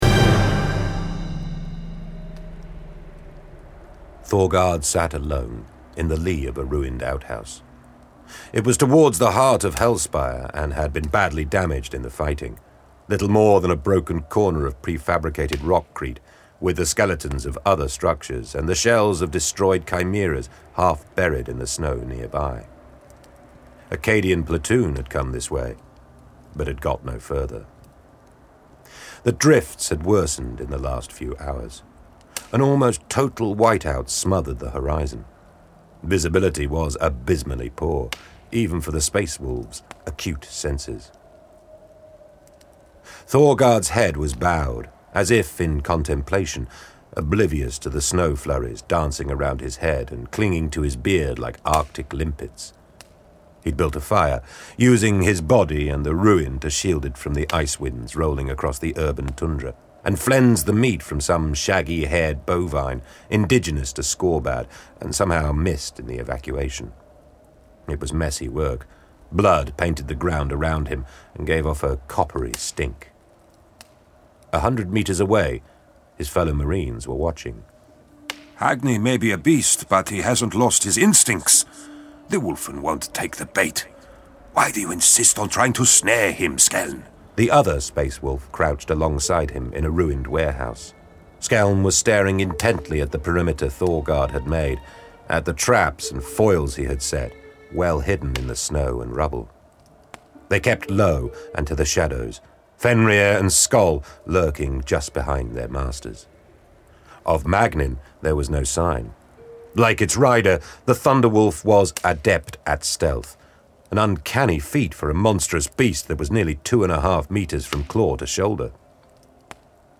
Index of /Games/MothTrove/Black Library/Warhammer 40,000/Audiobooks/Thunder from Fenris